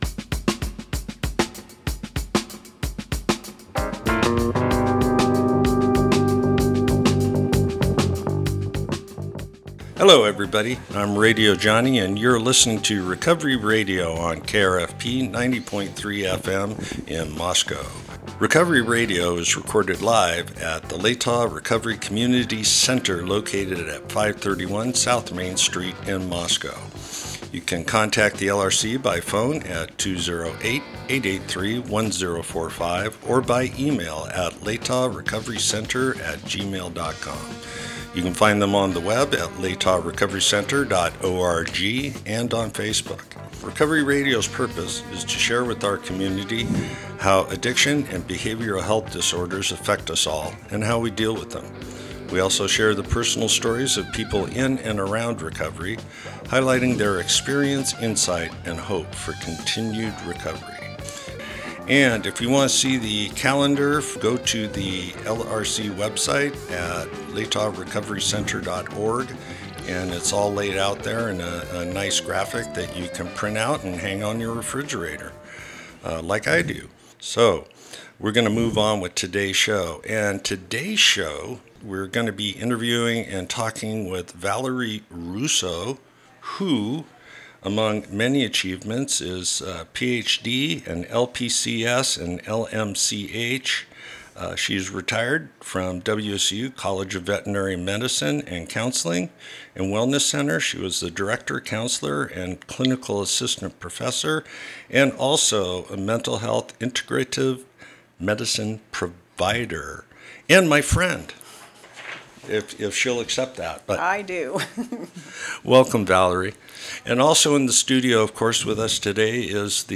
Type: Interview